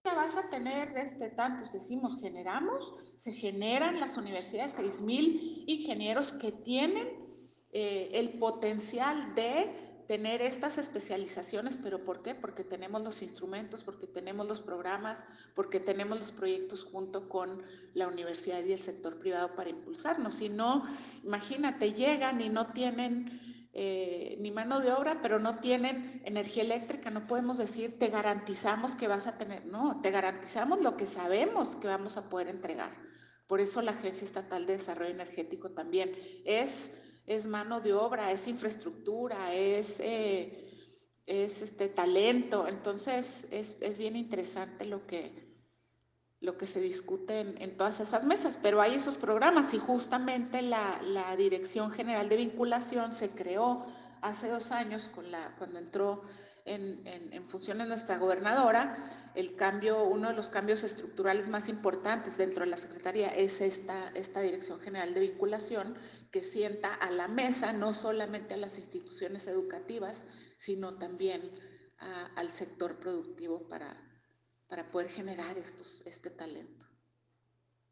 AUDIO: MARÍA ANGÉLICA GRANADOS, TITULAR DE LA SECRETARÍA DE INNOVACIÓN Y DESARROLLO ECONÓMICO (SIDE)